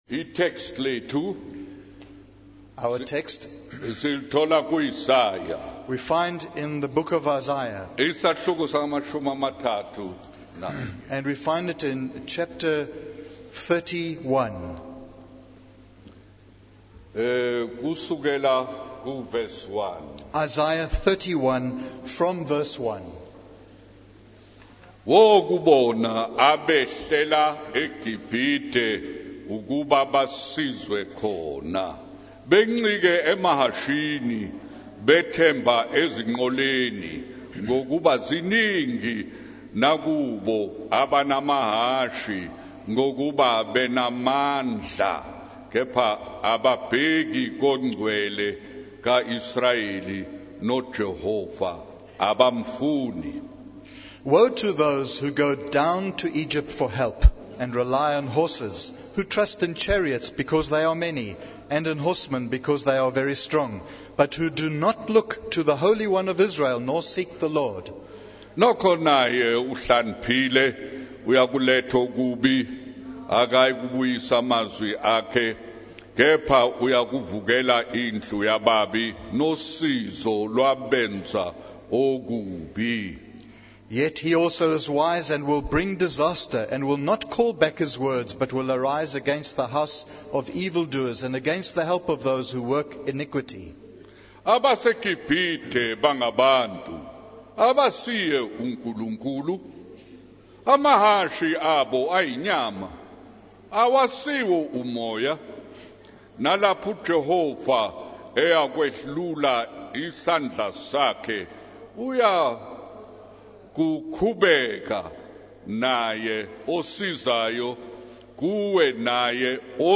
In this sermon, the preacher discusses the importance of trusting in God rather than relying on worldly wealth and success. He shares a story about a visit from an older person who advised against starting a water factory, stating that it would not succeed in the market. The preacher emphasizes that preachers, in particular, should not be consumed by worries about money and should instead trust in God for their provision.